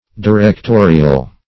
Directorial \Di*rec*to"ri*al\, a. [Cf. F. directorial.]